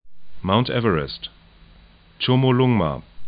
'maʊnt 'ɛvərɛst
tʃomo'lʊŋma